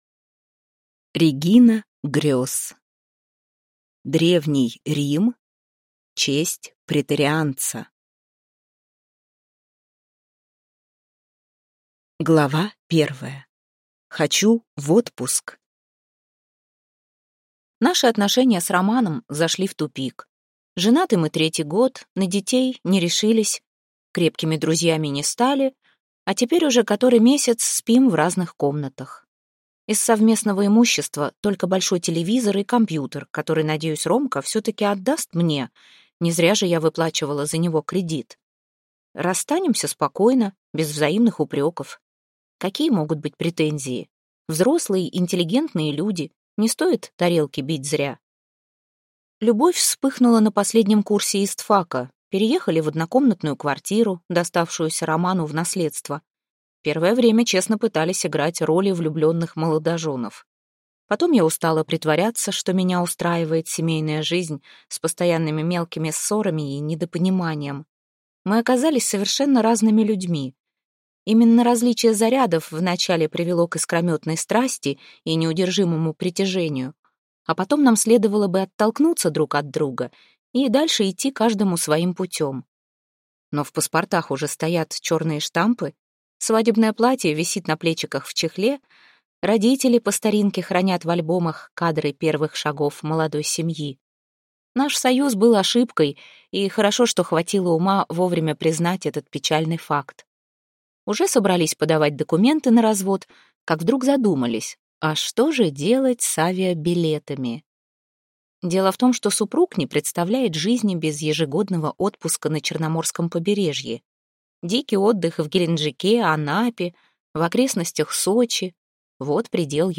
Аудиокнига Древний Рим. Честь преторианца | Библиотека аудиокниг